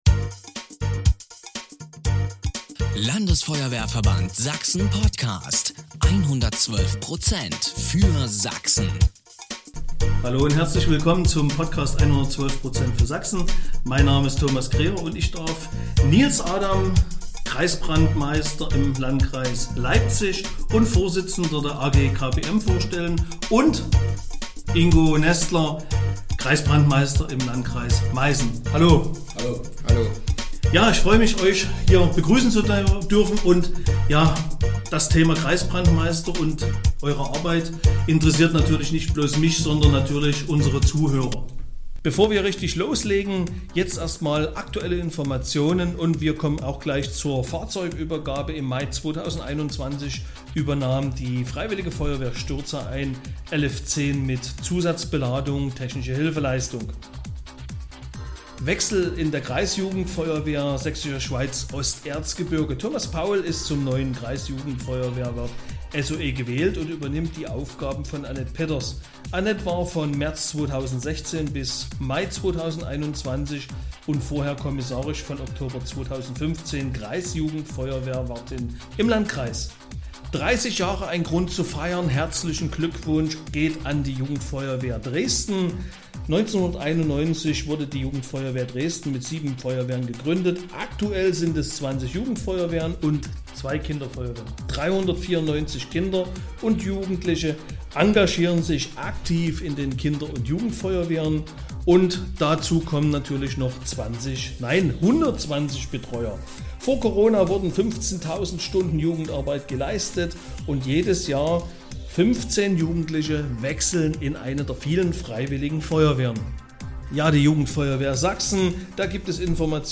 Wir sind gemeinsam ins Gespräch gekommen und das Arbeitsumfeld der KBM beleuchtet.